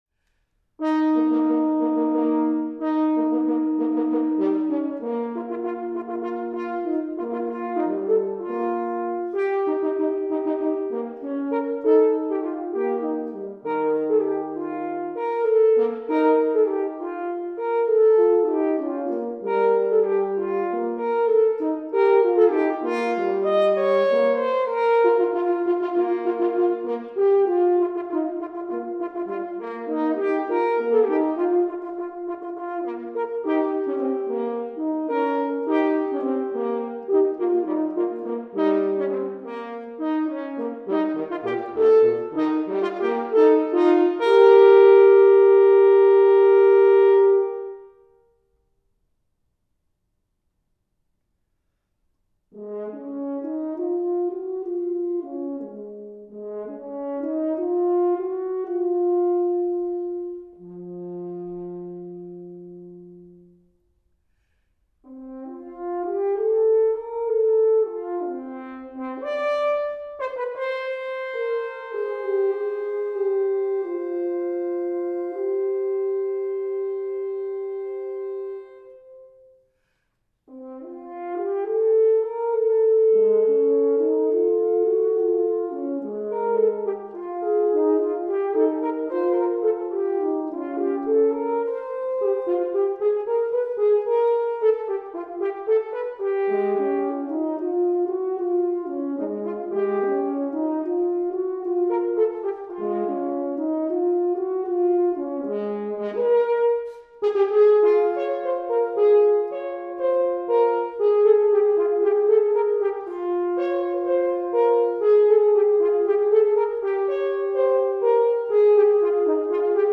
horns